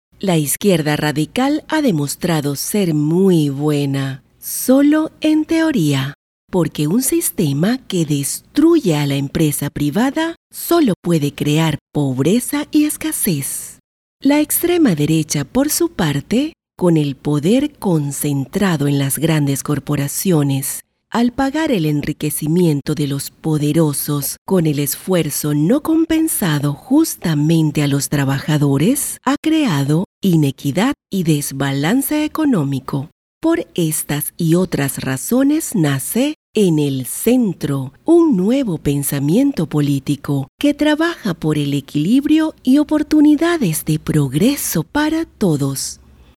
Female
Spanish (Latin American), Spanish (Venezuela)
Yng Adult (18-29), Adult (30-50)
Institutional, inspirational, compelling, educated, smooth, formal.
Political Spots